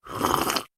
slurp.ogg